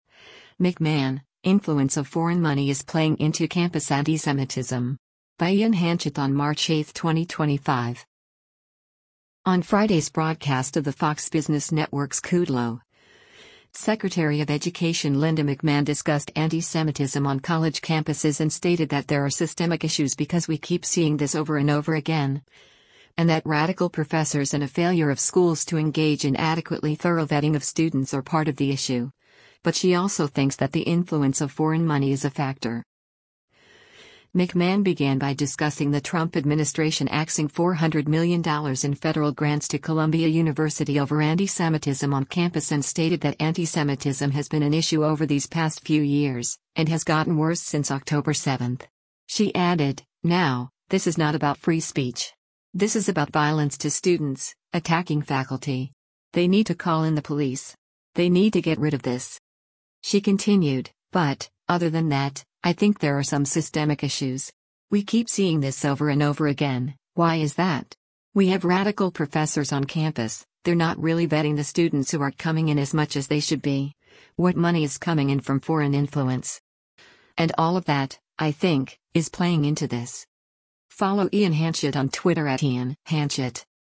On Friday’s broadcast of the Fox Business Network’s “Kudlow,” Secretary of Education Linda McMahon discussed antisemitism on college campuses and stated that there are “systemic issues” because “We keep seeing this over and over again,” and that “radical professors” and a failure of schools to engage in adequately thorough vetting of students are part of the issue, but she also thinks that the influence of foreign money is a factor.